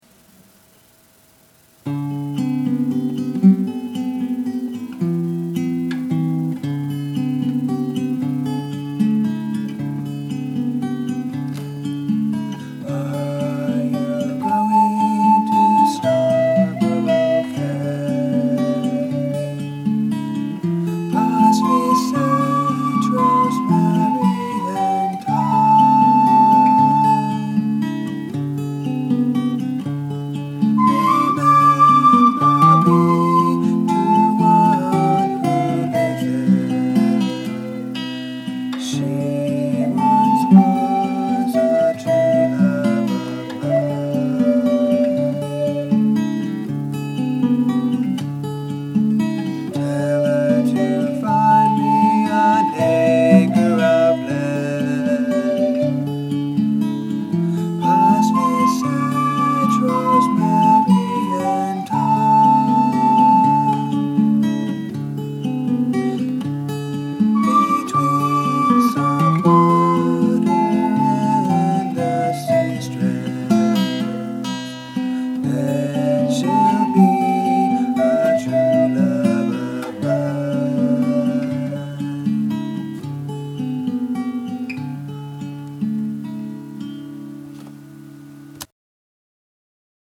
Scarborough Fair: Guitar and Ocarina
The first audio clip is the original recording, and the second one has a voice recording which I added later.
I think the volumes of the instruments and the voice are not proportioned adequately in the second recording.